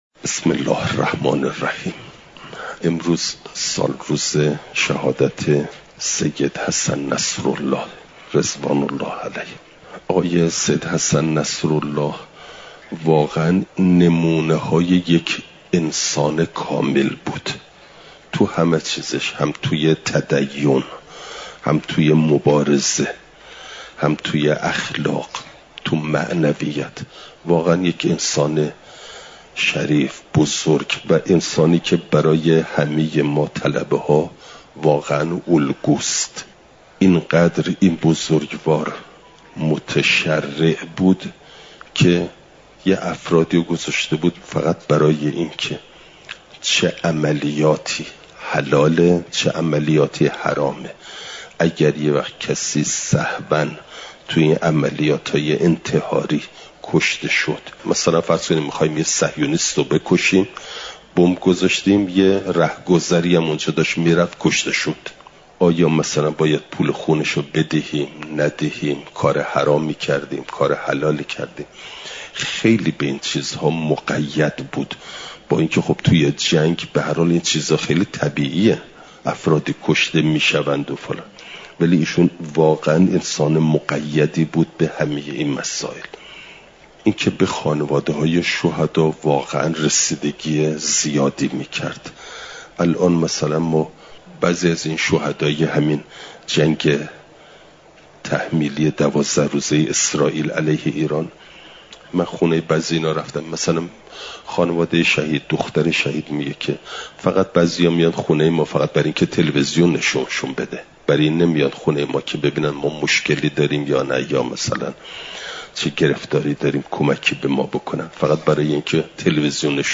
یکشنبه ۶ مهرماه ۱۴۰۴، حرم مطهر حضرت معصومه سلام‌ﷲ‌علیها